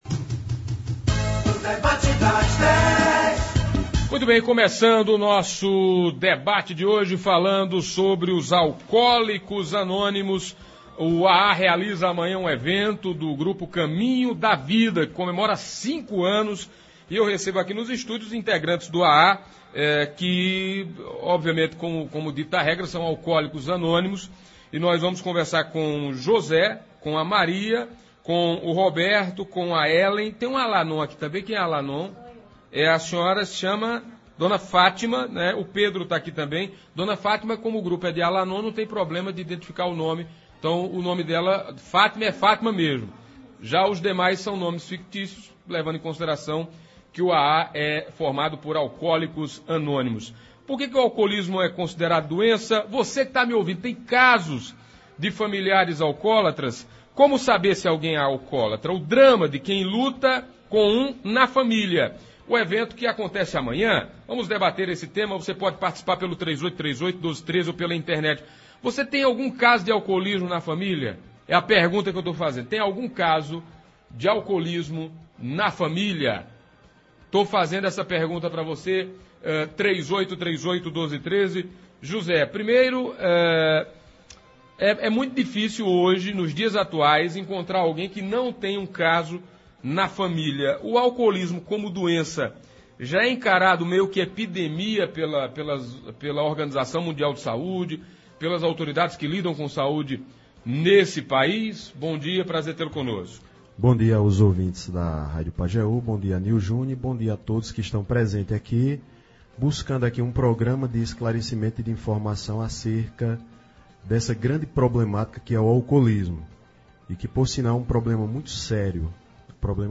Hoje nos estúdios da Pajeú, um grupo de pessoas que fazem parte dos Alcoólicos Anônimos, Caminho da Vida, falaram sobre o que é alcoolismo, porque alcoolismo é considerado doença, quais são os indicativos do problema e o drama familiar.
Foi um debate esclarecedor. Os participantes fizeram relatos de suas vidas antes e pós o álcool, falaram de como chegaram ao fundo do poço e conseguiram se reerguer com a ajuda do A. A, como é a luta diária para se manterem sóbrios e da importância de perceber e aceitar a doença.